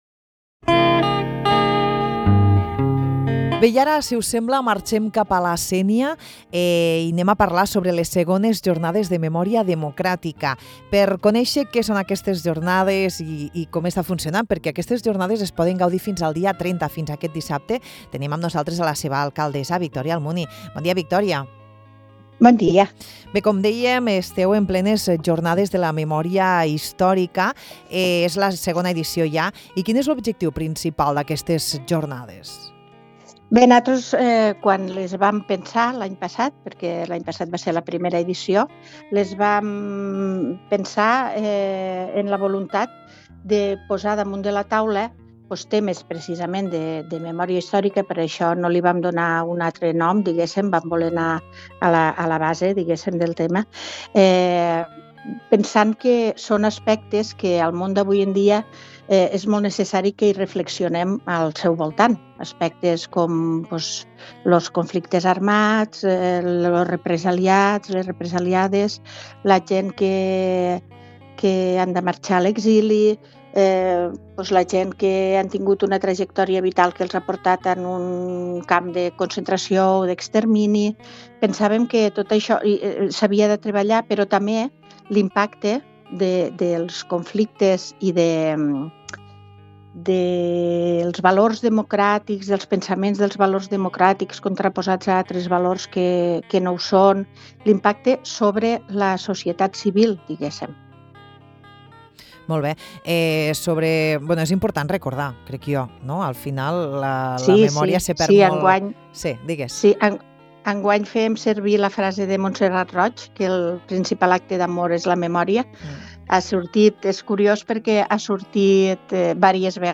Avui ens ha acompanyat al ‘De Bon Matí’ l’alcaldessa de la Sénia Victòria Almuni per parlar sobre aquesta segona edició de les Jornades de Memòria Democràtica que s’estan duent a terme al municipi. En l’entrevista, entre altres, l’alcaldessa destaca la importància de recordar la nostra història per evitar repetir els errors del passat.